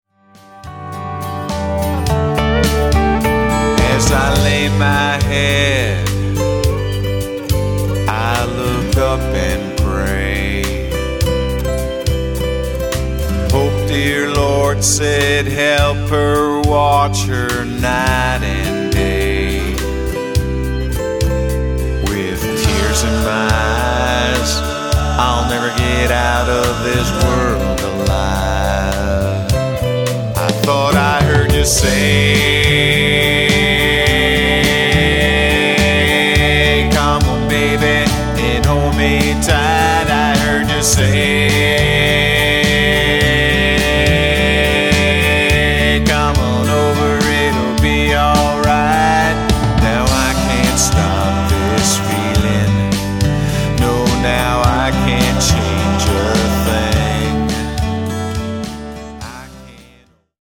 Electric/acoustic lead guitar
bass guitar and vocal harmonies
pedal steel and leslie organ